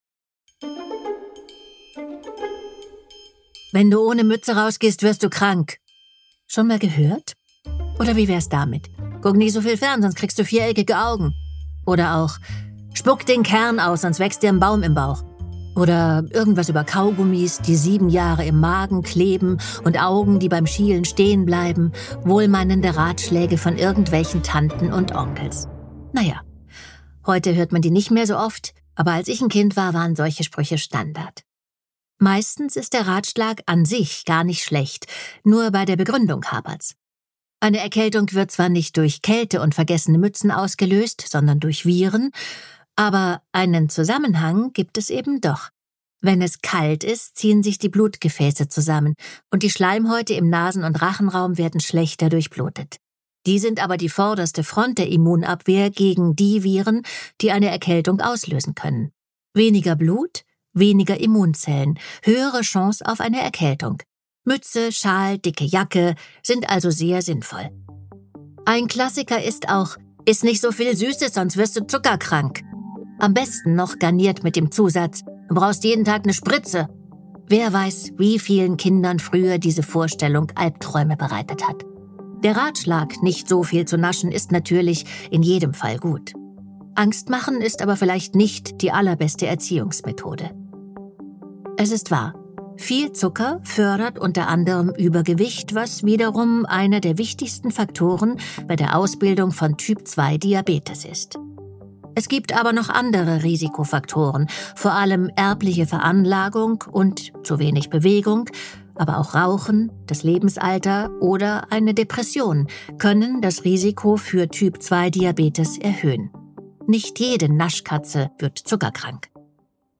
Wie aus einem Todesurteil eine beherrschbare Krankheit wurde und welche Rolle Ameisen bei der Geschichte von Diabetes spielen, erzählt Andrea Sawatzki in dieser Folge.